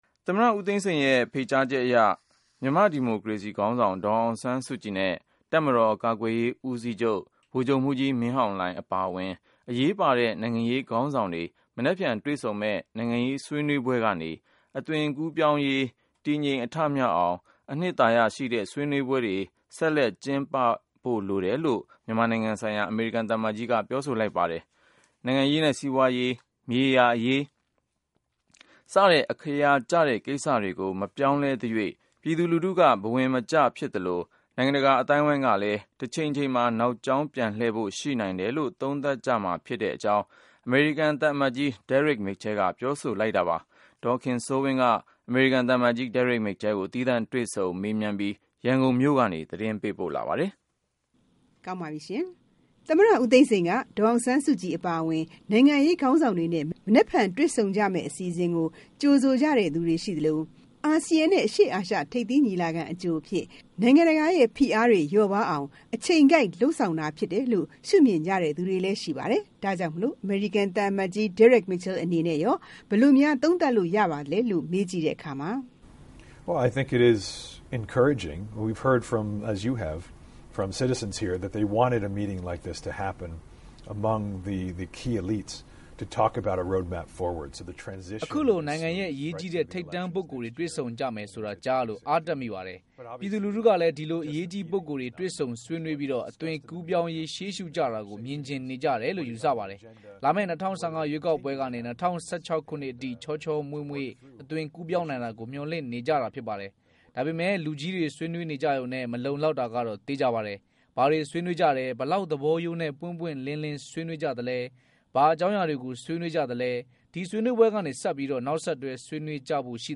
Exclusive interview with US Ambassador Derek Mitchell